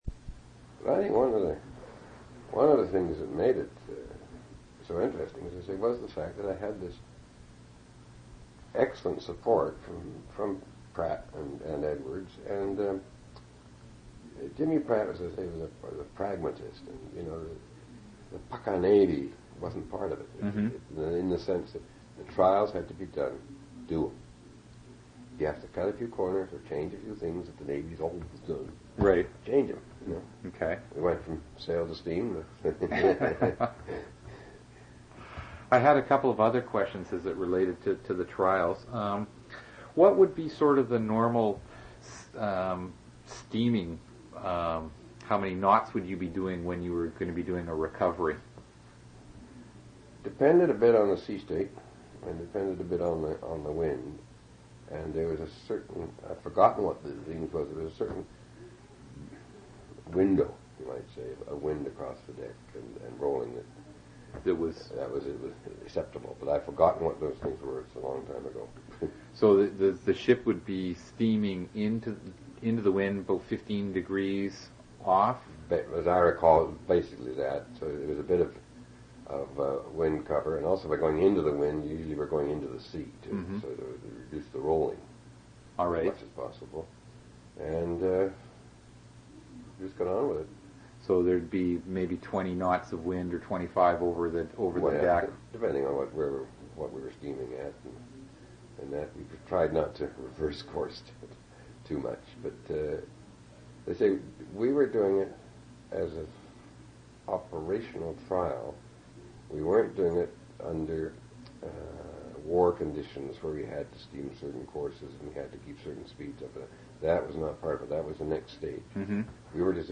One original audio cassette in Special Collections.
oral histories